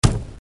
Tonfo medio
Rumore tonfo generico di oggetto che urta.
THUDBOX2.mp3